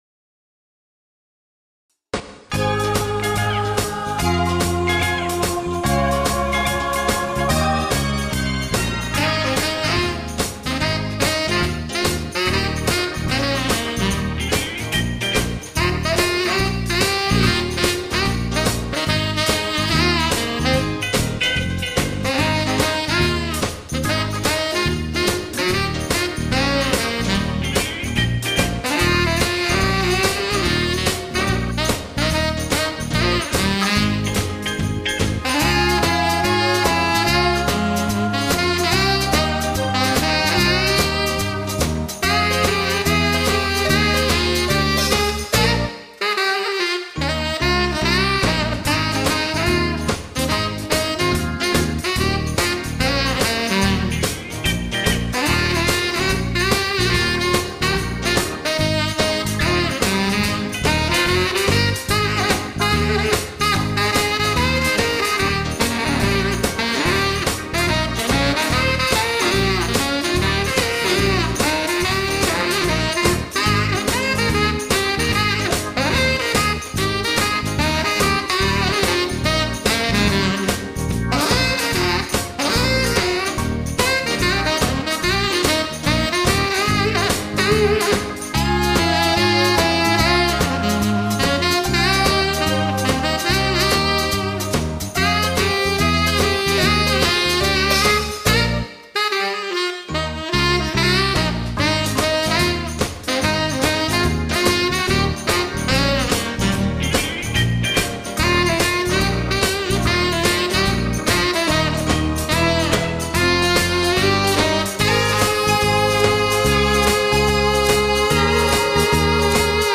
Christmas Saxophone Music